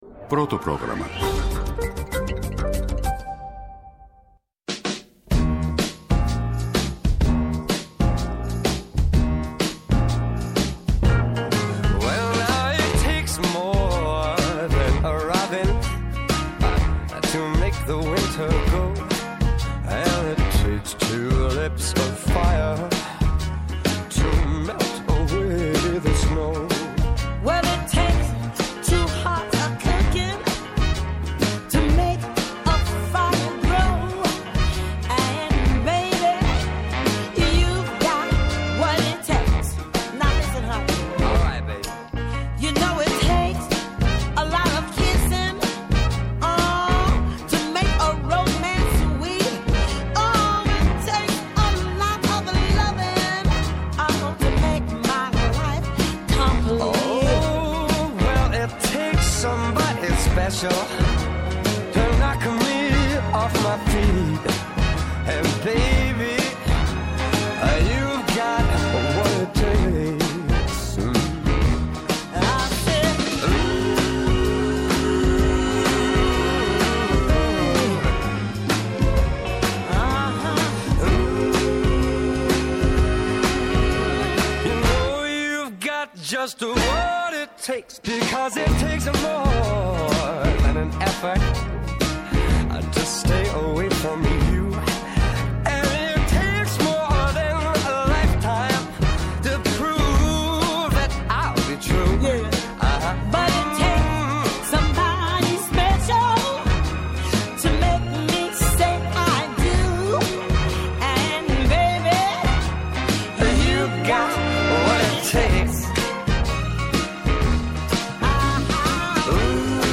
-O Αλέξανδρος Καχριμάνης, περιφερειάρχης Ηπείρου.